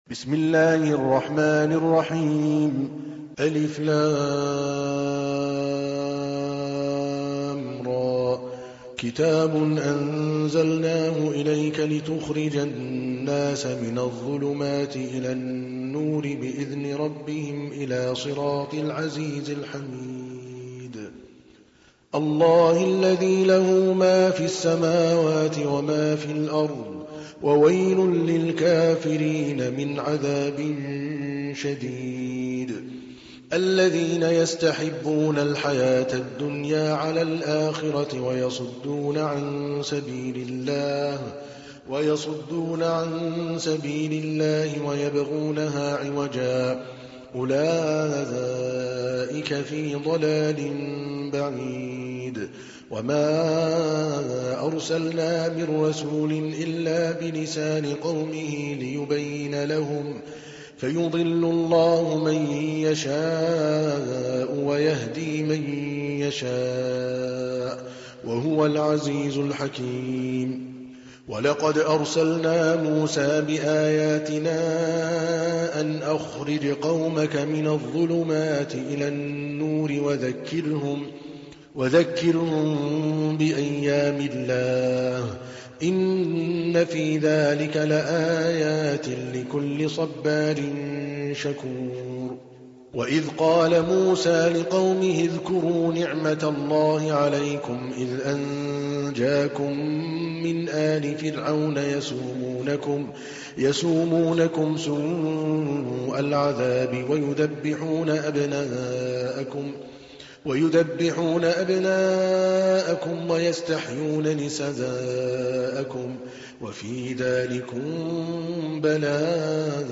تحميل : 14. سورة إبراهيم / القارئ عادل الكلباني / القرآن الكريم / موقع يا حسين